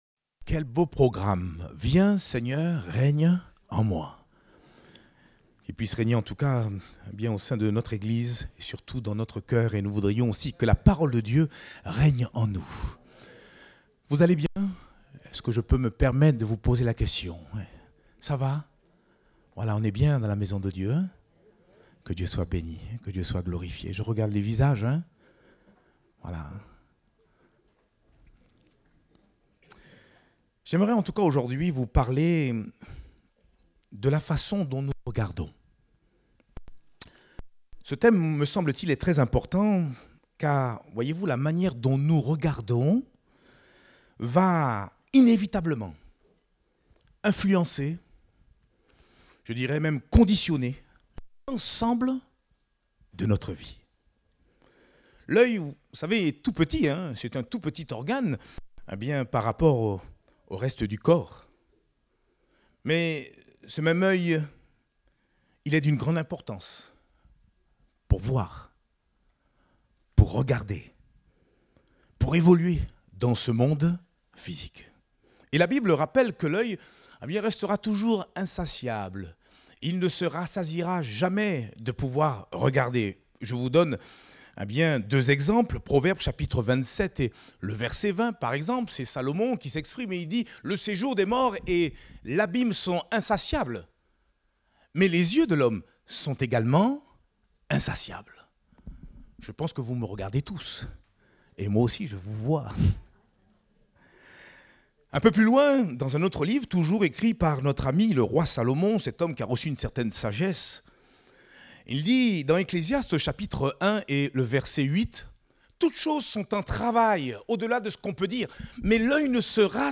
Date : 17 mars 2024 (Culte Dominical)